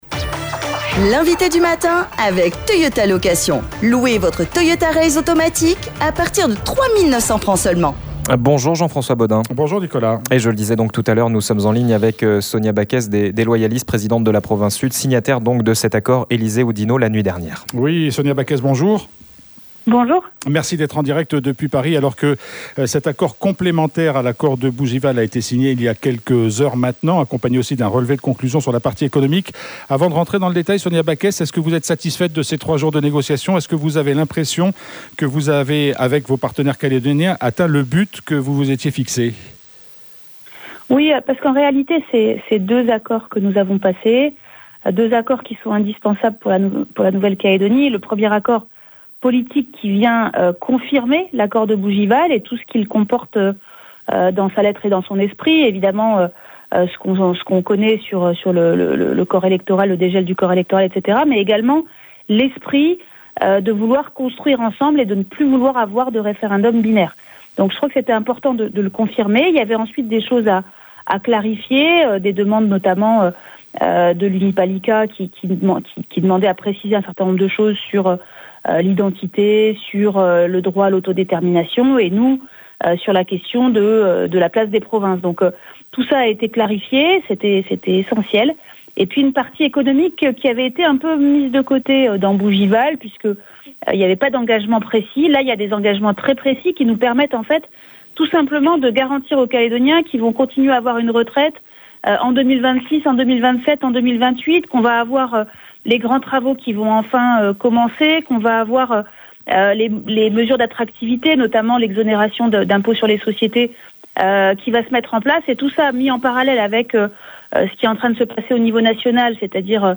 Après 3 jours de réunion à Paris, les formations politiques calédoniennes ont signé un accord de 4 pages précisant l’accord de Bougival. Nous en avons parlé avec Sonia Backès par téléphone.